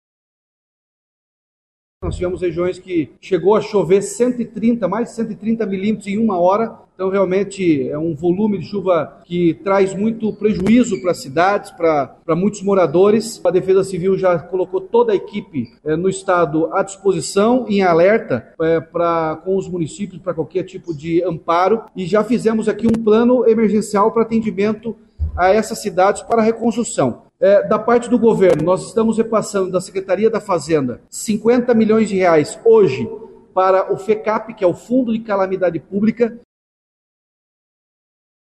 O governador Ratinho Junior (PSD) destacou as ações de apoio às cidades atingidas.